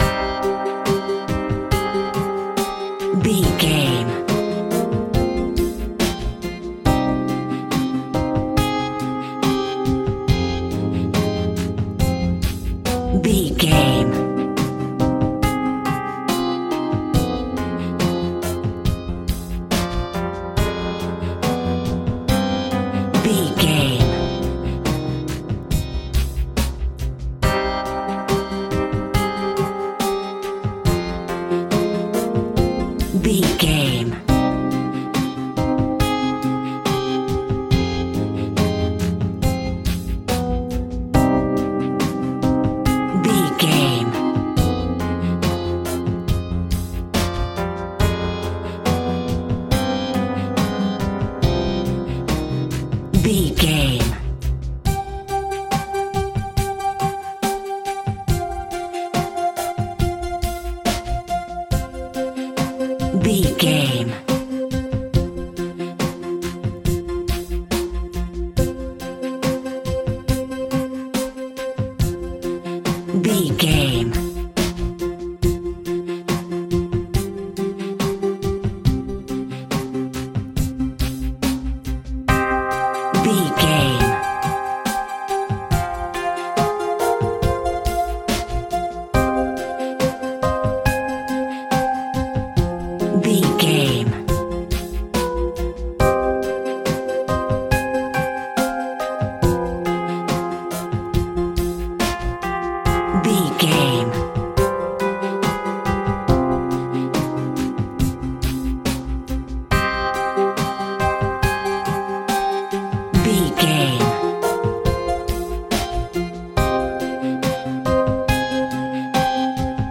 Aeolian/Minor
Slow
tension
ominous
dark
suspense
haunting
eerie
industrial
drums
synthesiser
electric piano
viola
orchestral instruments